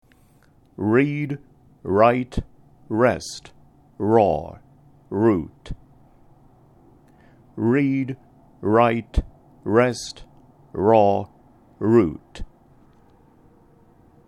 音声サンプル（read, right, rest, raw, root　同上）
PronunciationSamplesR.mp3